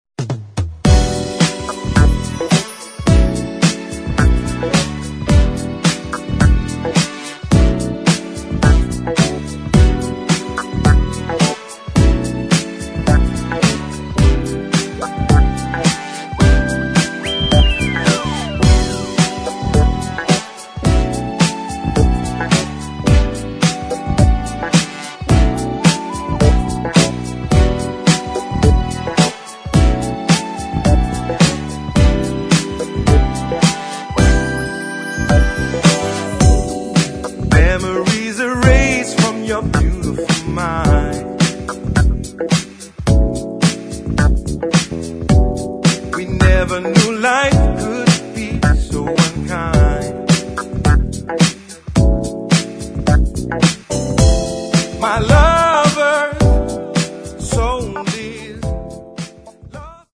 [ UK SOUL ]